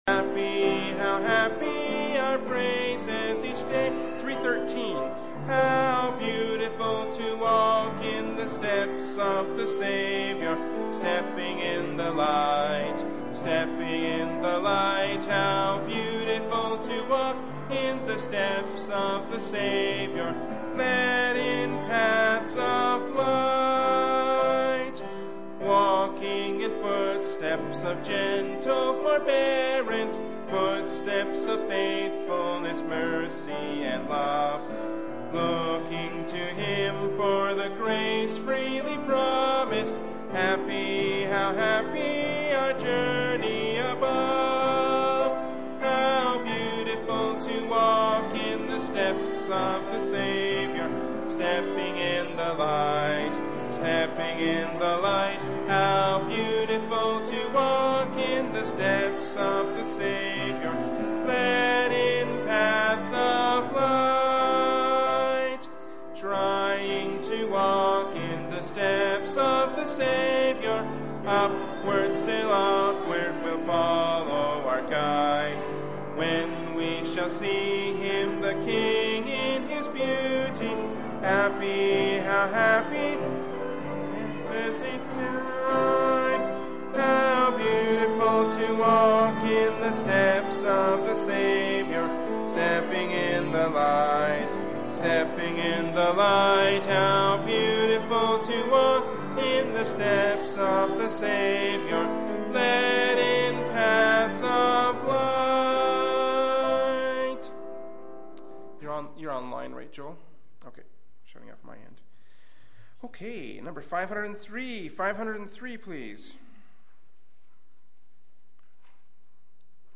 Prayer Meeting